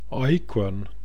Ääntäminen
Synonyymit ekorre Ääntäminen : IPA: /aɪkwœ̞nn/ Haettu sana löytyi näillä lähdekielillä: ruotsi Käännöksiä ei löytynyt valitulle kohdekielelle.